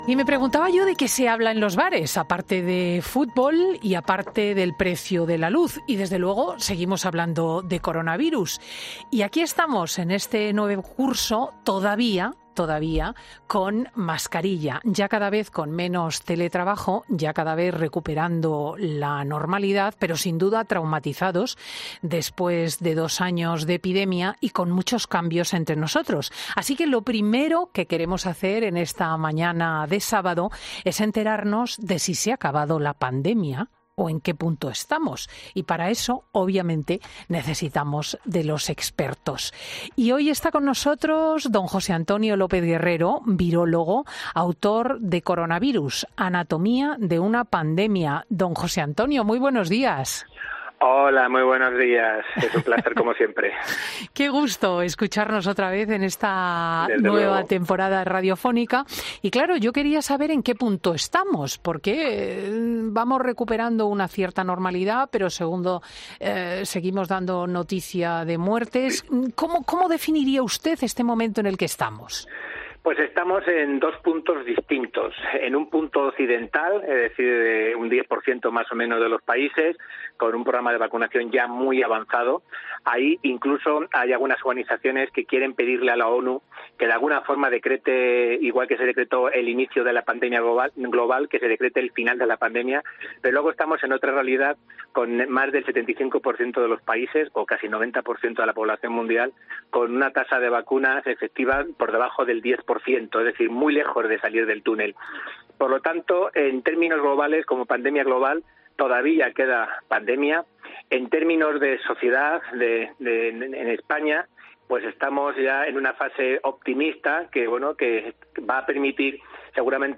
El virólogo explica en COPE por qué se está dando la infección de Covid en personas que ya han recibido las dosis de la vacuna